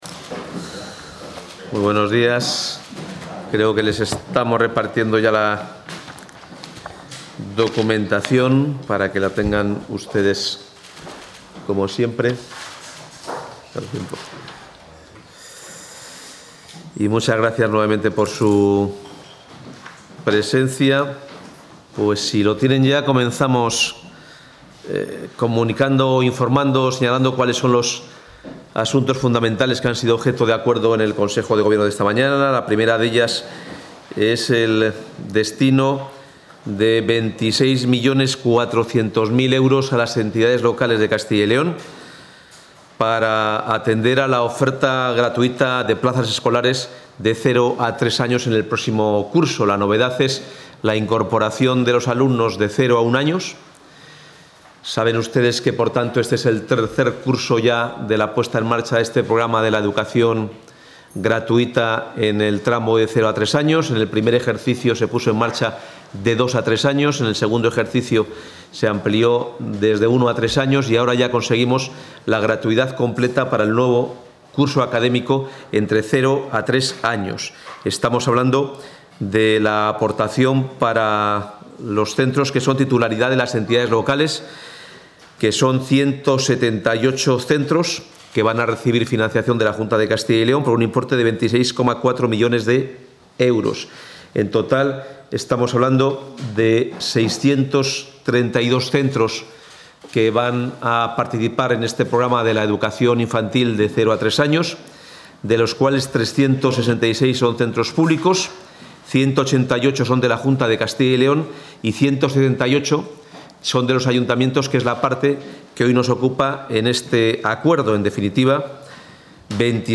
Intervención del portavoz.